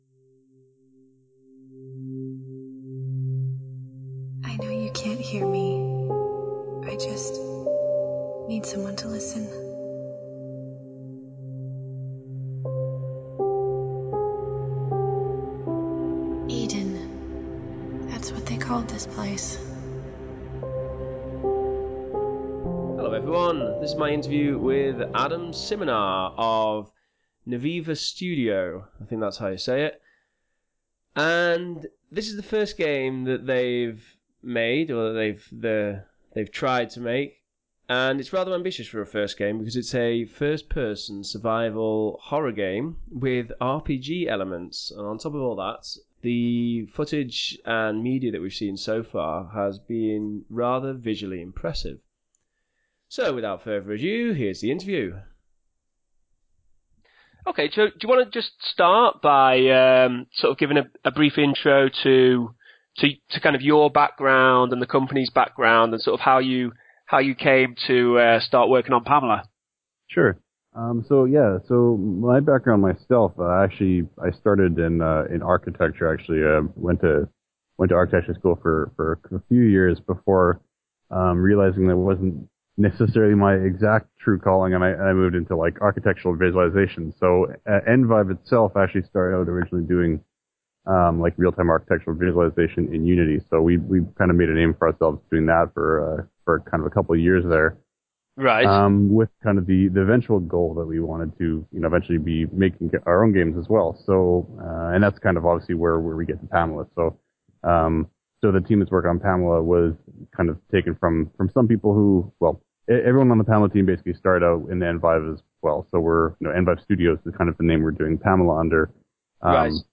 P.A.M.E.L.A. Interview